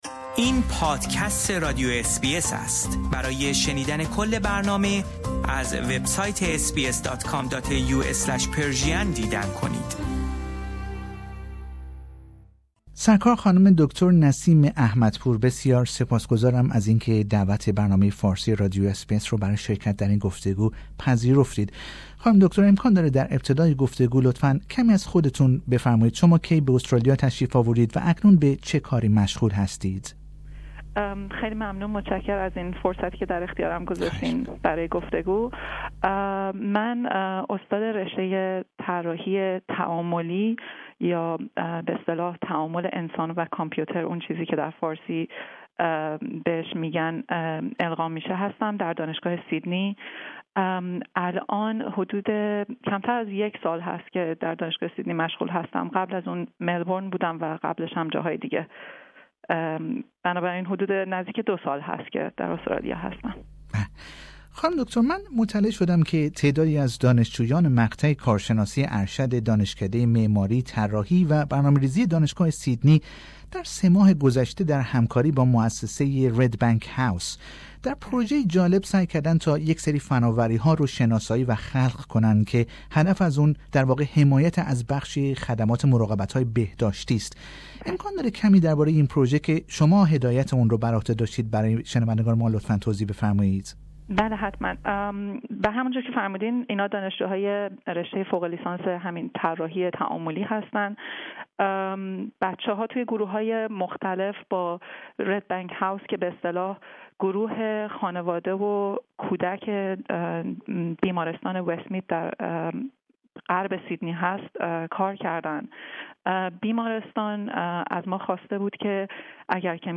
در گفتگو با بخش فارسی رادیو اس بی اس درباره این پروژه جالب سخن می گوید.